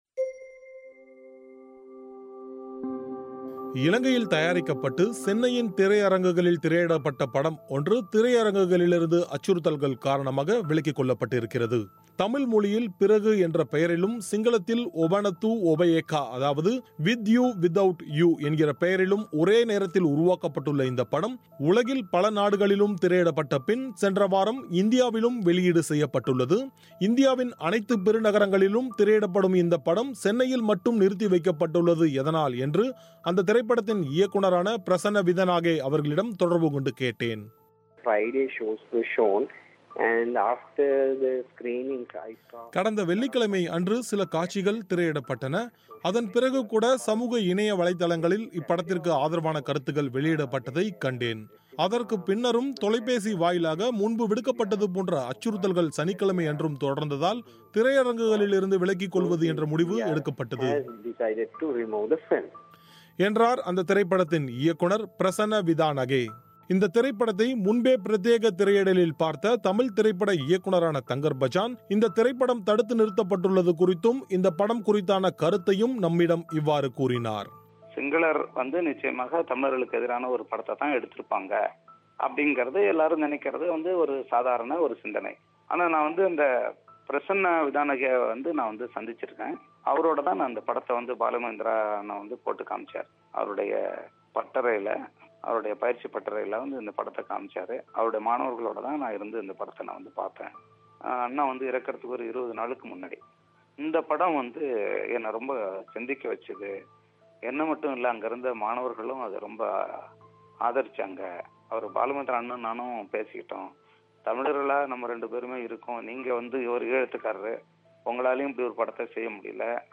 இவை குறித்த செய்திகளை ஒலி வடிவில் தொகுத்து வழங்குகிறார்